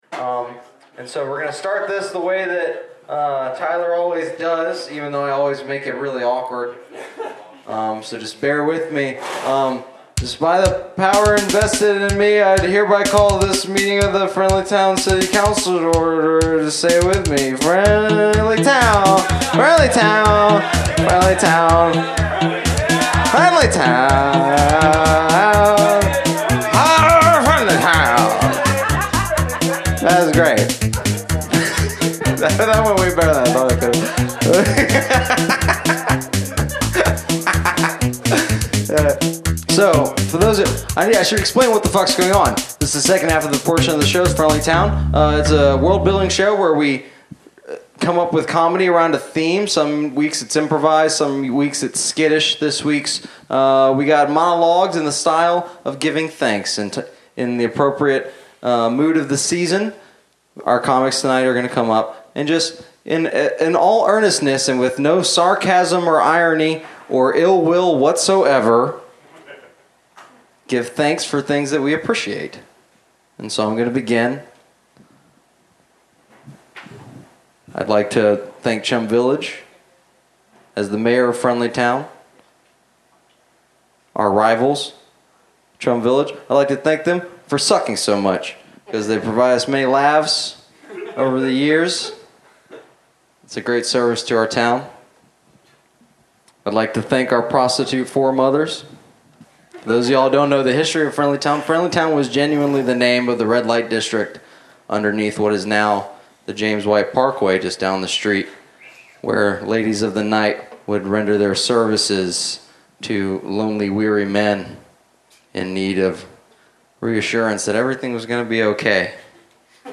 Recorded Live at the Pilot Light November 20, 2017, Knoxville TN Share this: Share on X (Opens in new window) X Share on Facebook (Opens in new window) Facebook Share on Pinterest (Opens in new window) Pinterest Like Loading...